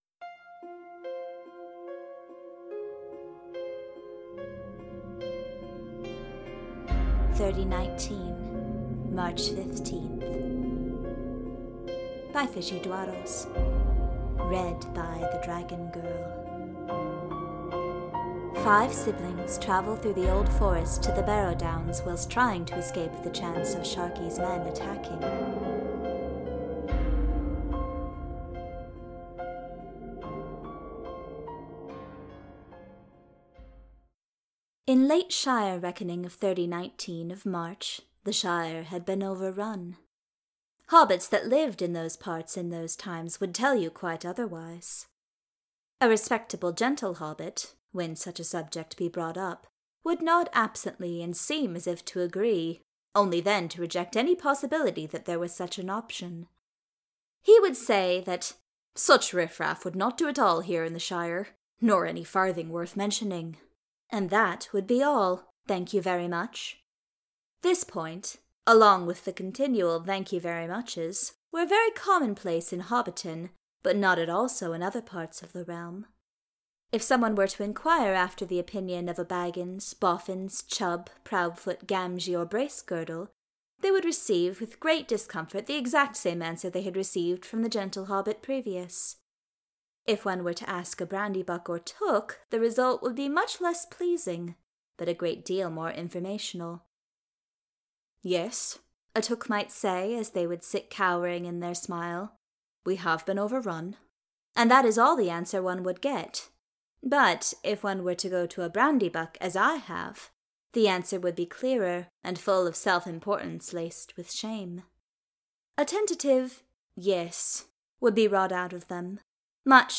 March 15th [Podfic]